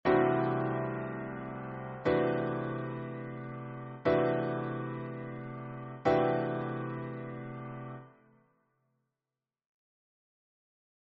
Acordes mayores oncena aumentada CM11 y variaciones
Acordes-mayores-oncena-aumentada-CM11-y-variaciones.mp3